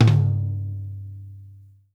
FLAMFLOOR2-R.wav